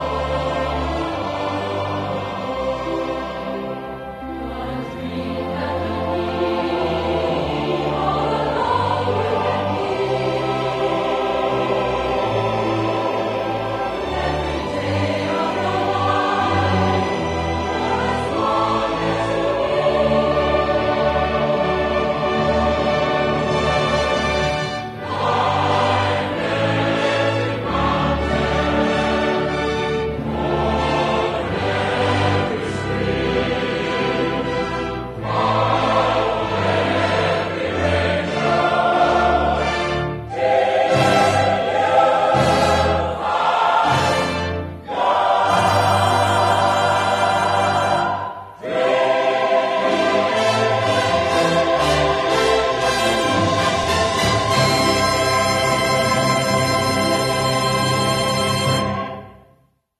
✨🎶 Our Sound of Music: Youth Edition opens in just 5 days, and the Sisters of Nonnberg Abbey are lifting their voices in stunning harmony as they prepare to welcome Maria — and you — to this timeless tale of faith, family, and finding your voice.